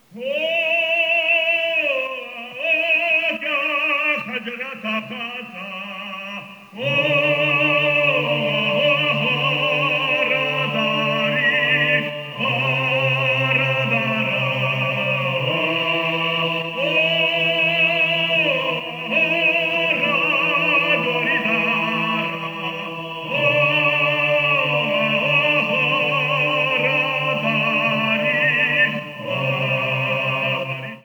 5 November 2017 at 7:02 pm IMO the singing style is characteristic of male choirs in the Western Caucasus region.